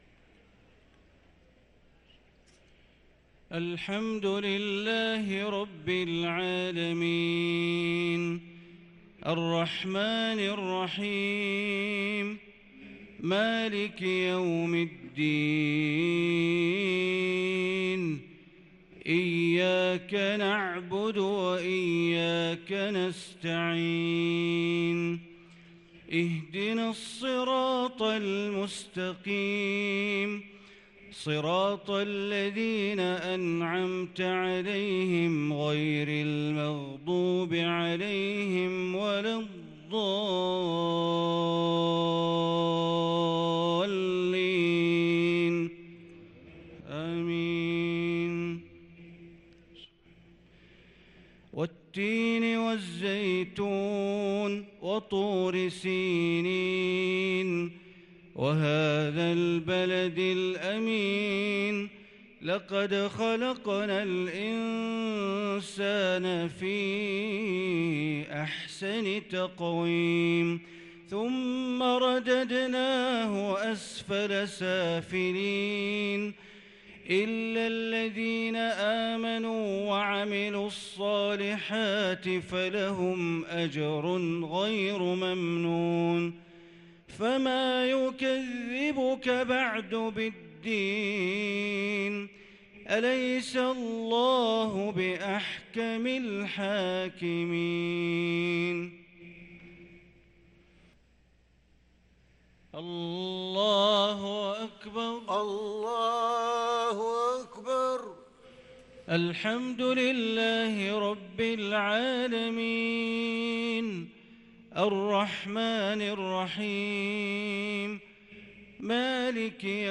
صلاة العشاء للقارئ بندر بليلة 11 رمضان 1443 هـ
تِلَاوَات الْحَرَمَيْن .